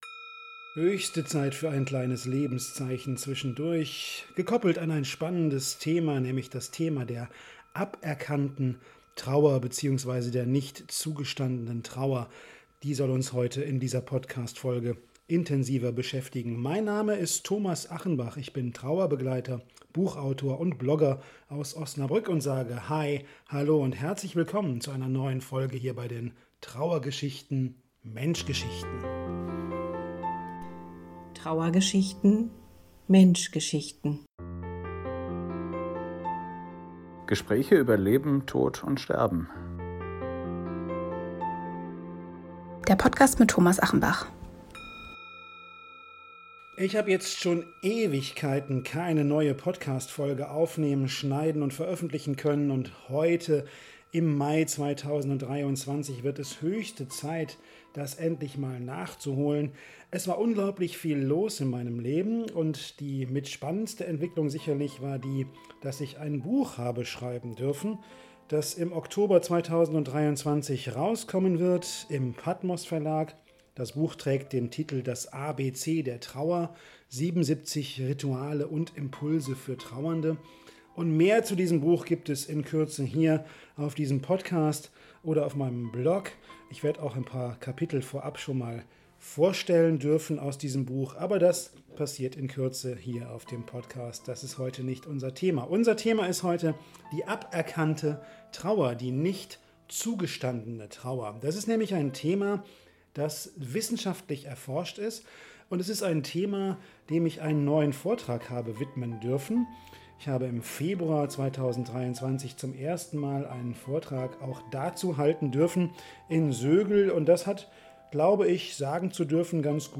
# 11 - Wenn kein Mensch Deine Trauer verstehen kann... - ein Vortrag über das Konzept der Aberkannten Trauer ~ Trauergeschichten - Menschgeschichten Podcast
11_Trauergeschichten_Menschgeschichten_Aberkannte_Trauer_Vortrag.mp3